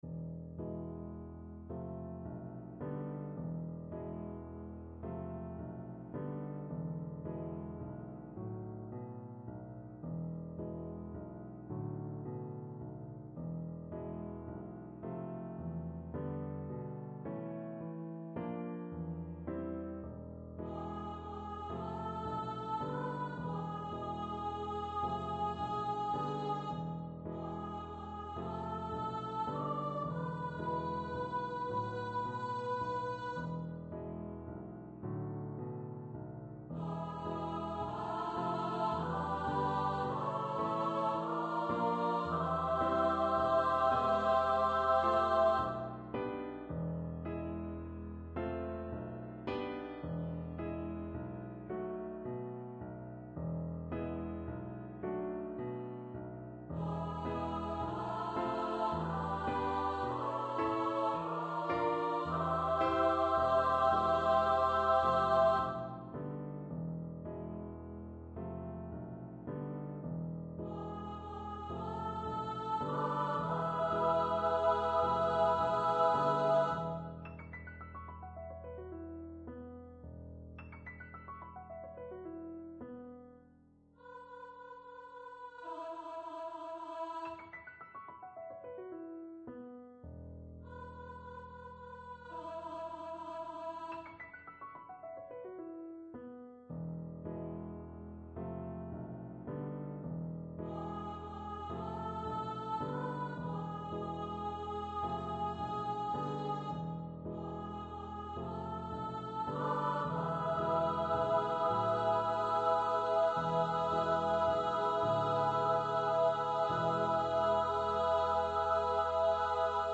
for piano and upper voice choir
Choir - 3 part upper voices